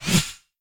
whooshForth_Farthest.wav